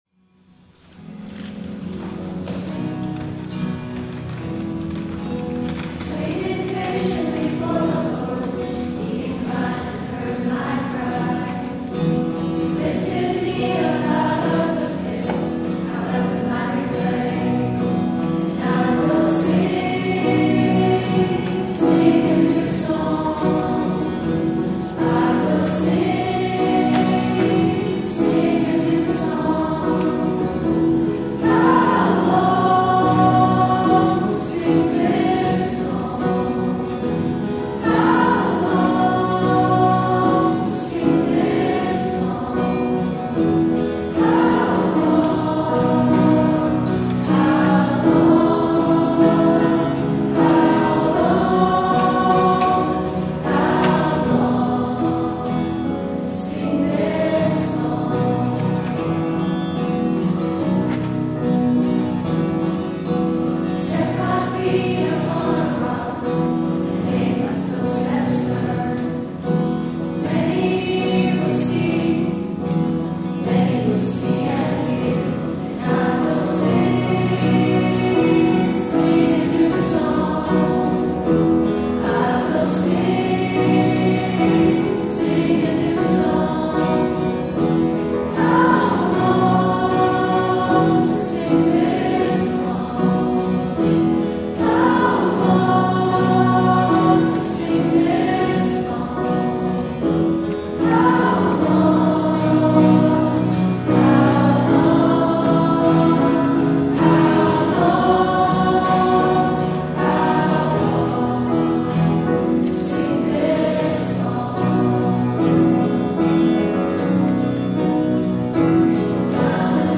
THE ANTHEM
The Youth Choir